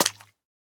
Minecraft Version Minecraft Version latest Latest Release | Latest Snapshot latest / assets / minecraft / sounds / entity / pufferfish / flop3.ogg Compare With Compare With Latest Release | Latest Snapshot
flop3.ogg